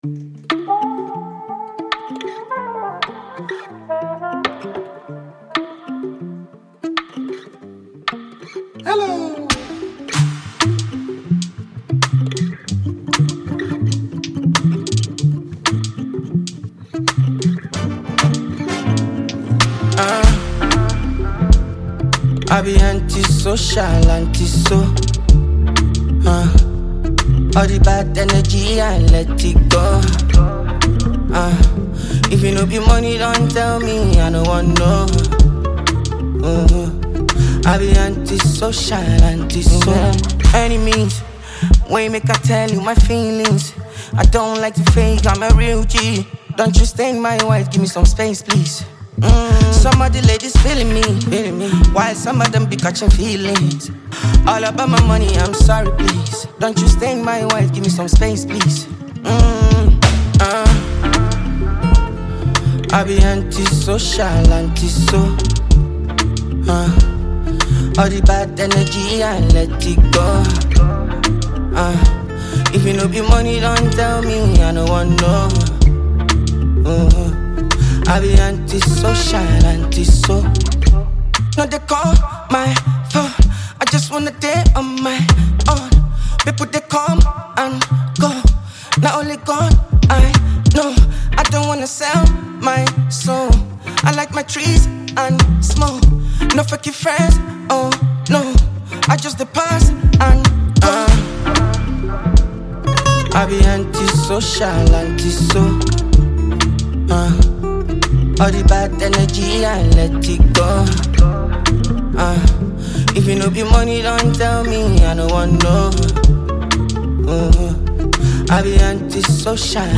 Ghanaian Afrobeat sensation